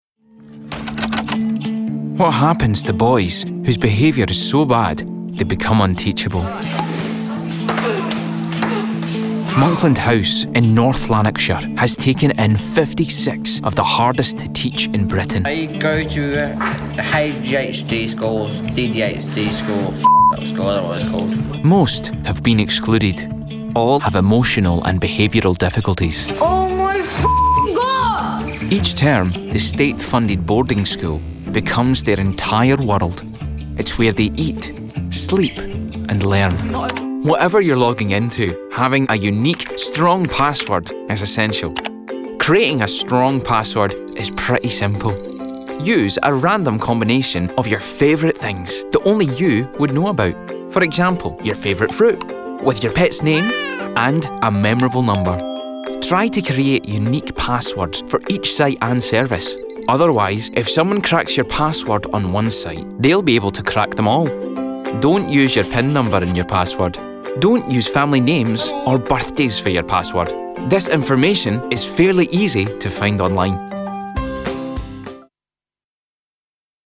Narration Reel
• Native Accent: Glasgow
• Home Studio